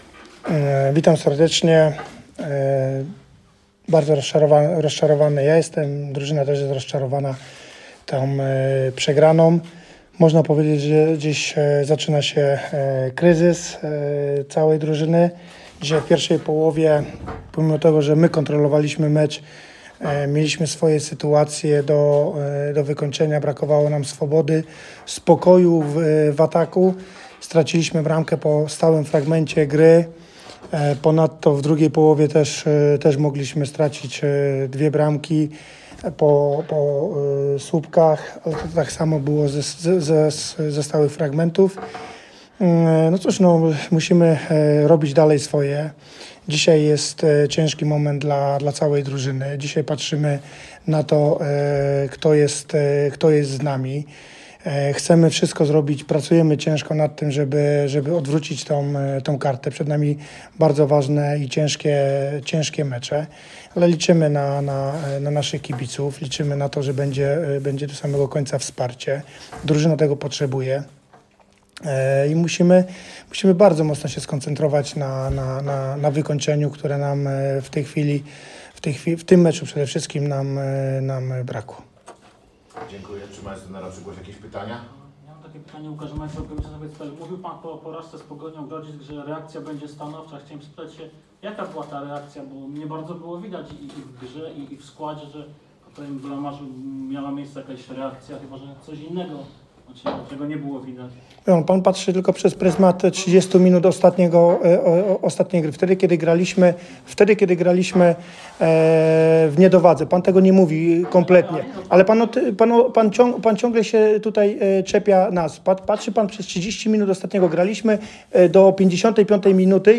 Zapraszamy do odsłuchania zapisu audio konferencji prasowej po meczu Wisły Puławy z Zagłębiem Sosnowiec.
Konferencja prasowa po meczu w Puławach
[konferencja prasowa]